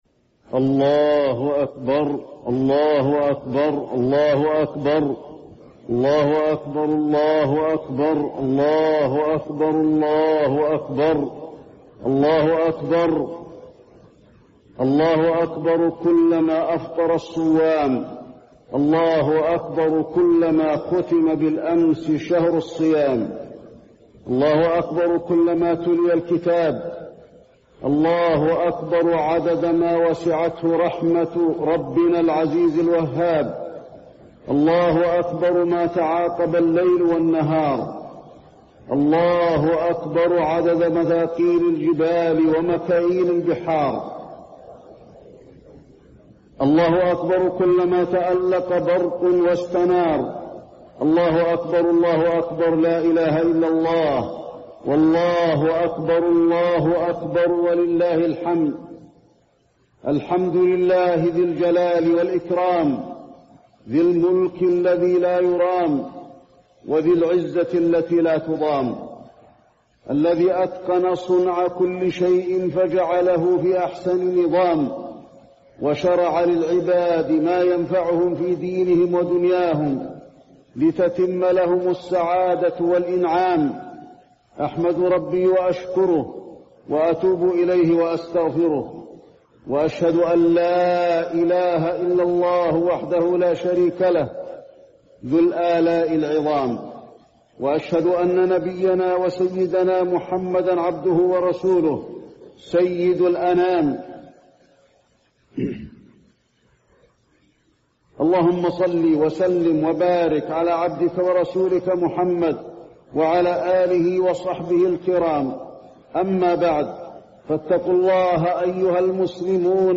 خطبة عيد الفطر - المدينة - الشيخ علي الحذيفي